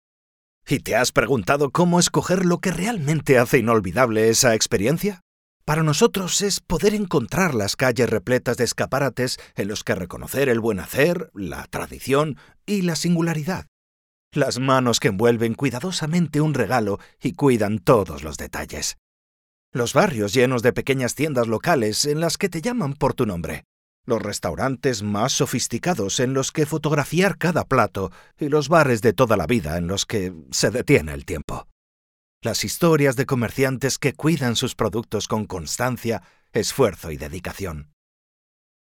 some of my jobs as online castilian spanish and basque voiceover
DEMO SPOT Comerciantes (CAST)